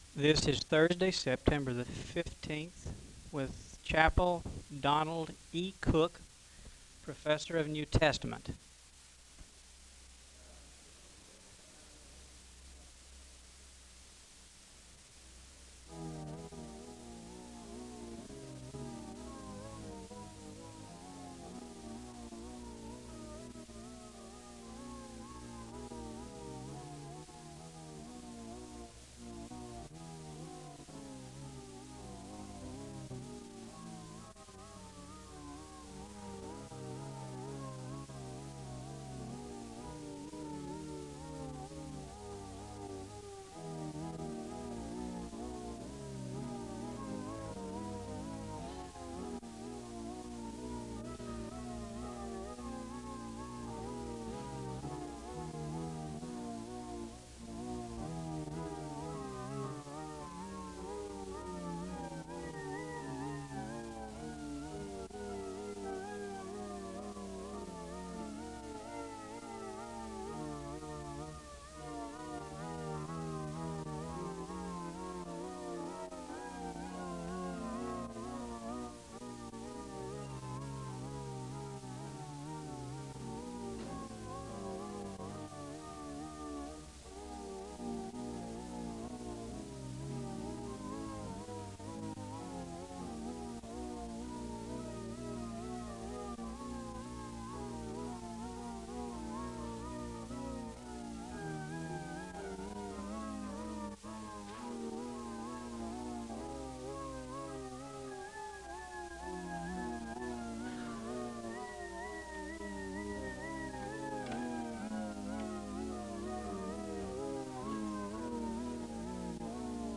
Two hymns are played (0:15-4:08). A word of prayer is given, followed by another hymn (cut) (4:09-4:36).
The choir sings a song of worship (7:10-9:11).
The sermon is concluded with a word of prayer (28:14-28:59).